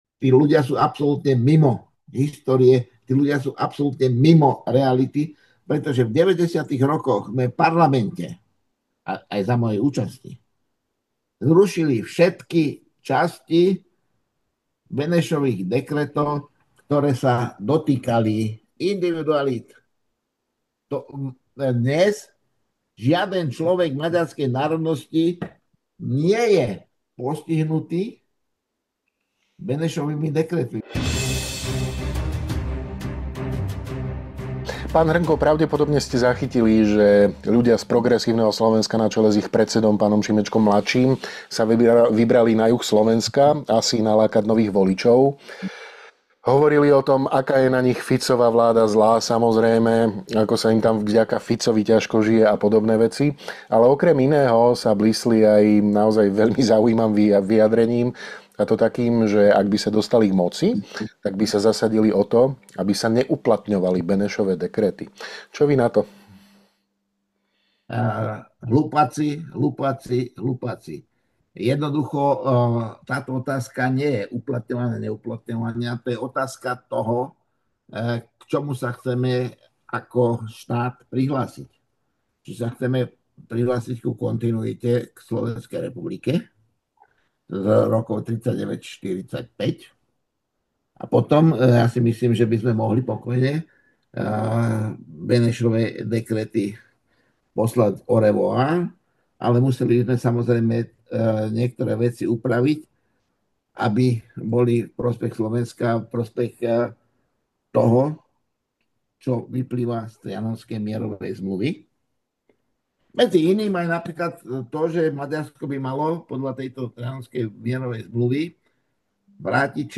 Rozprávali sme sa s historikom a bývalým politikom, PhDr. Antonom Hrnkom, CSc.